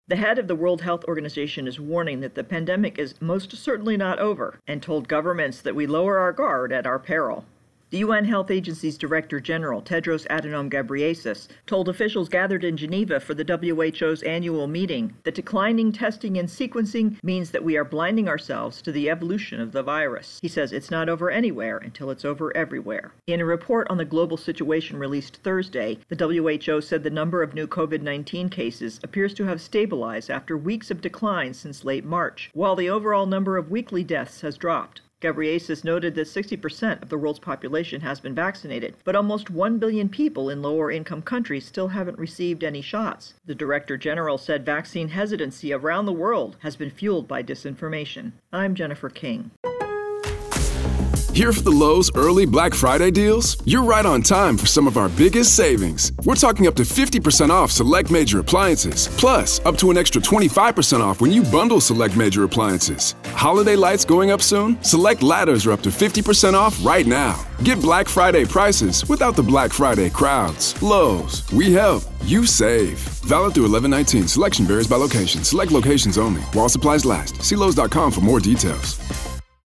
Virus Outbreak WHO intro and voicer